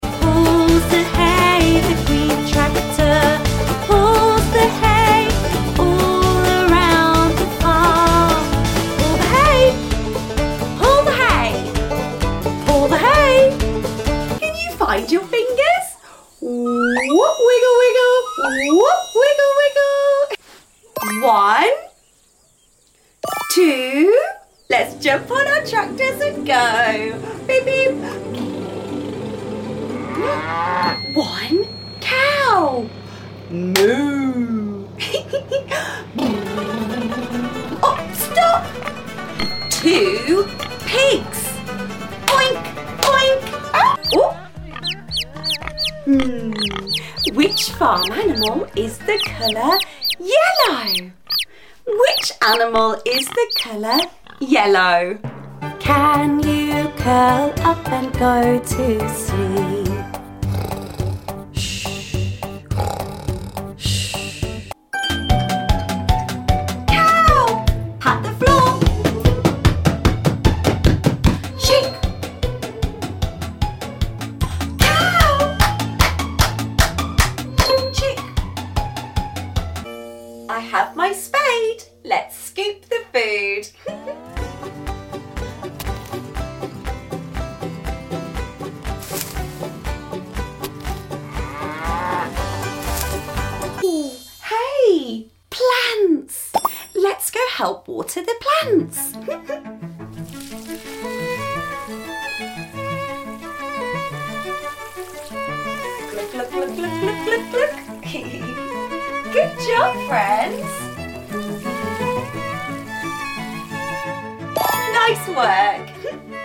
Learn Farm Animals & their sound effects free download
Learn Farm Animals & their Sounds